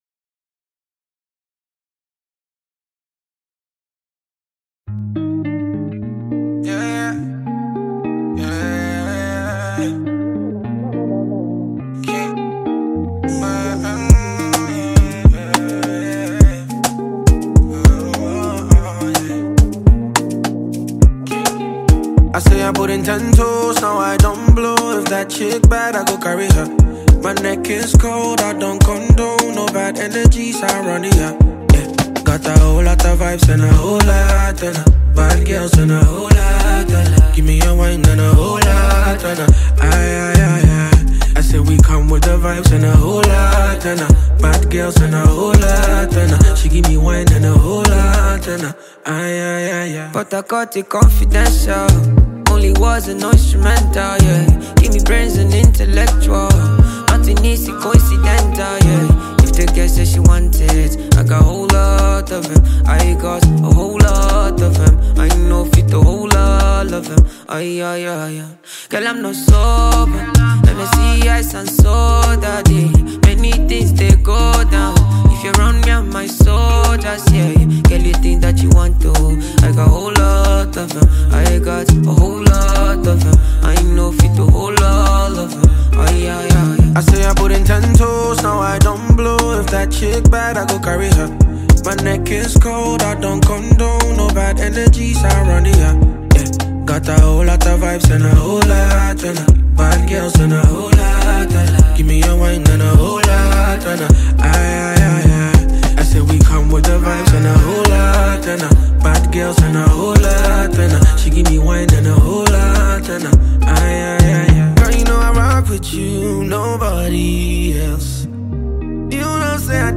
has come through with this urban-grooving collaboration.